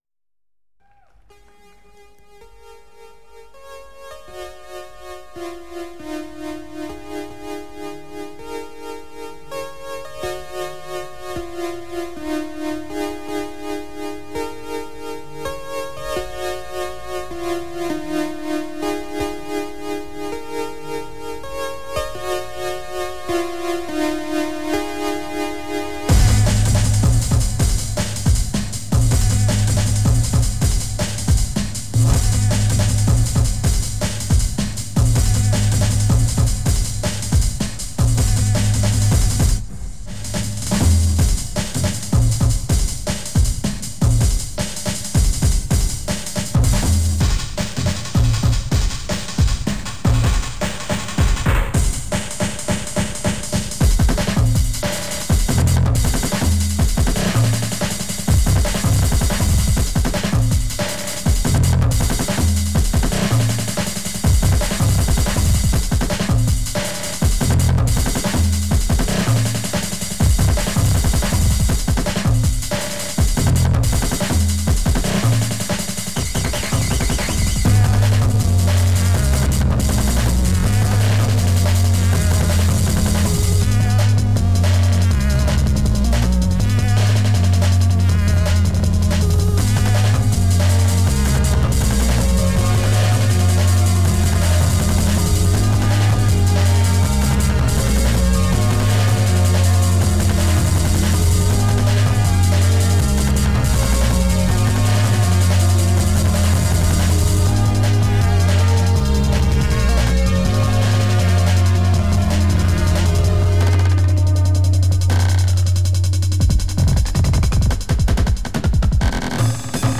event Roskilde Festival